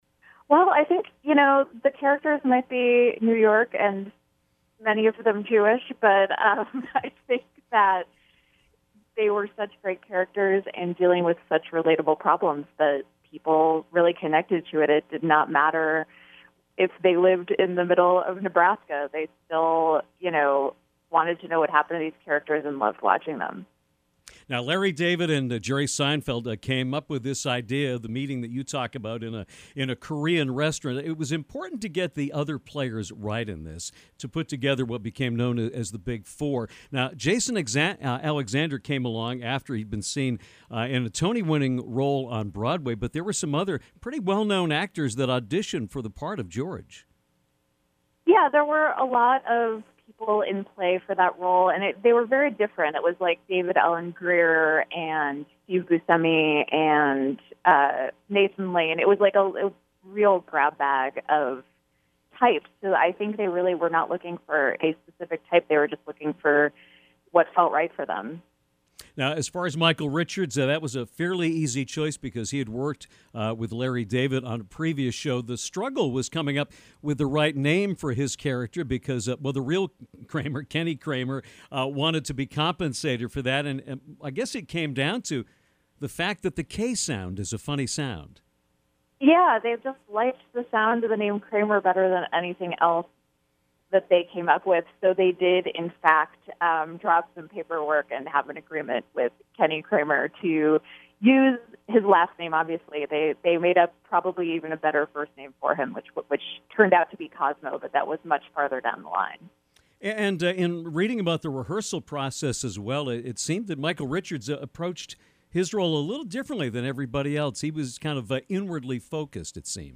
The author of “Seinfeldia” joined the Downtown show for a phenomenal conversation that toured the entire Seinfeld universe.